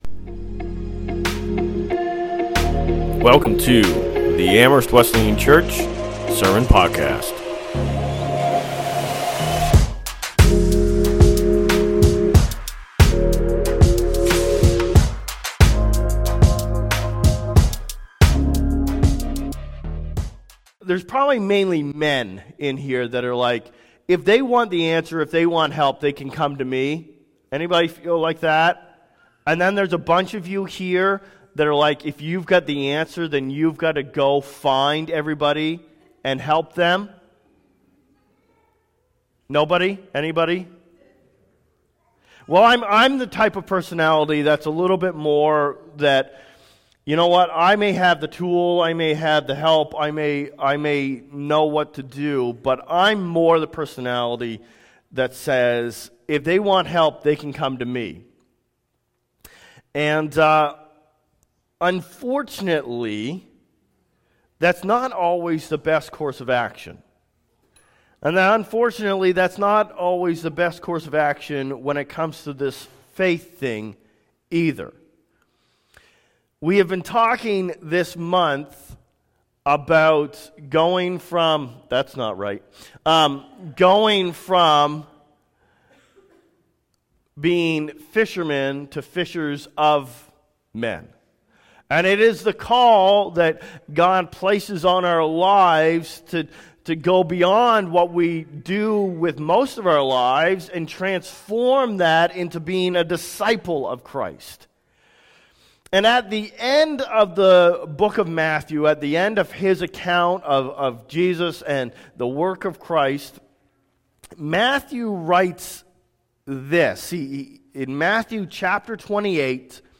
From Fishermen to Fishers of Men Current Sermon No Option Keeping the Gospel to yourself is not an option; you have to share it.